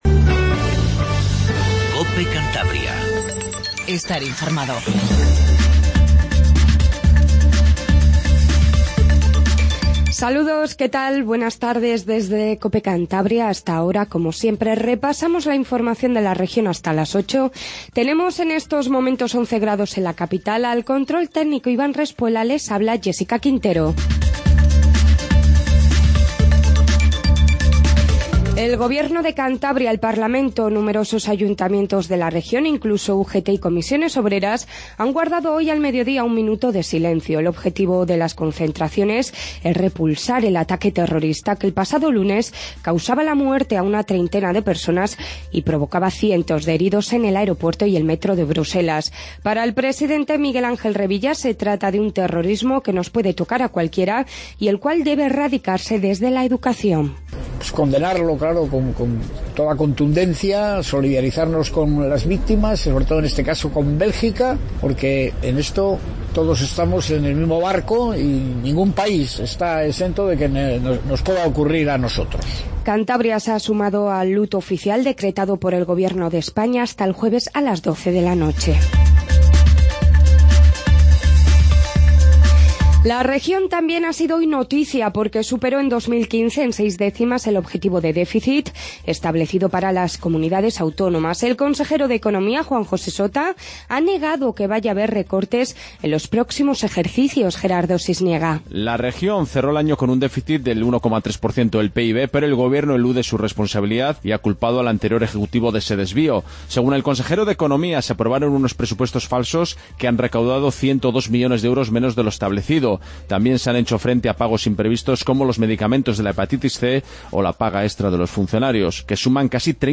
INFORMATIVO DE TARDE 19;50